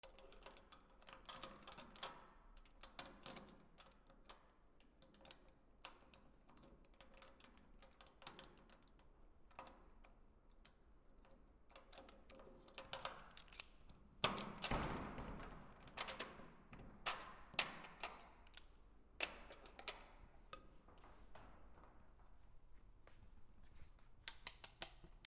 南京錠
/ K｜フォーリー(開閉) / K35 ｜鍵(カギ)